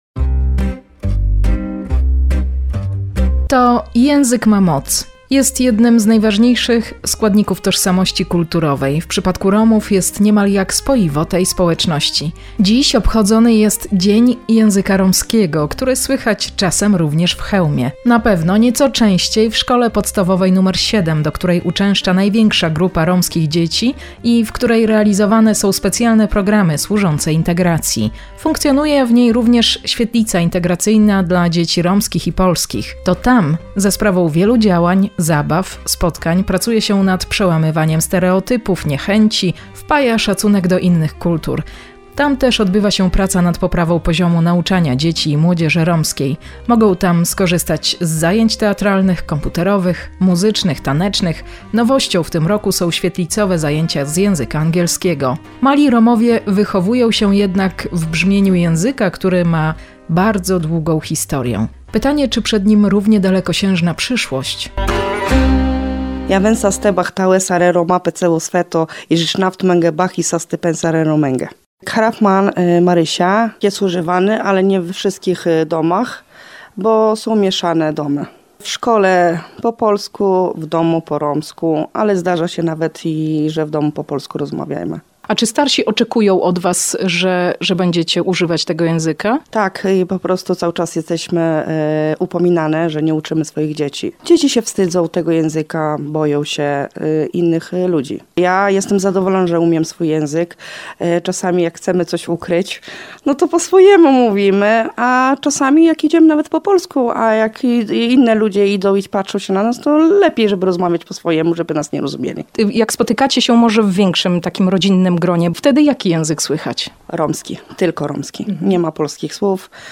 Bohaterki reportażu opowiadały o nadziejach na przyszłość tego języka i teraźniejszości romskich mieszkańców Chełma: